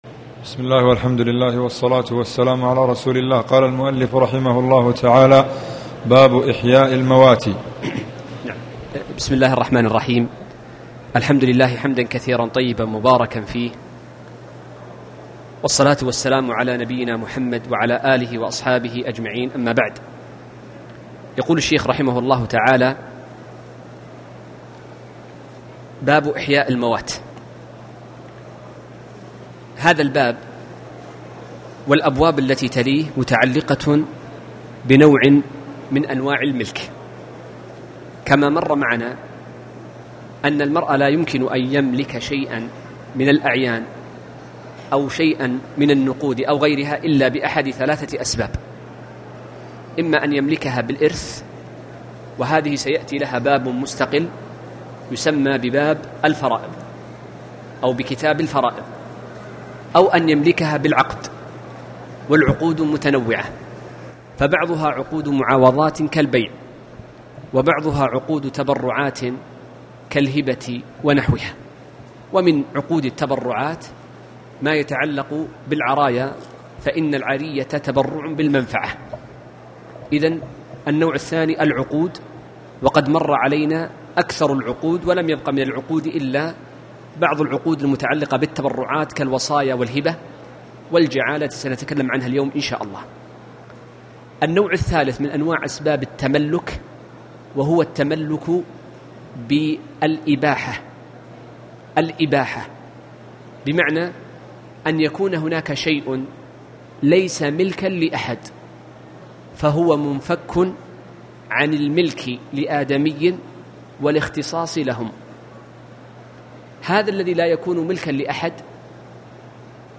تاريخ النشر ٢٤ محرم ١٤٣٩ هـ المكان: المسجد الحرام الشيخ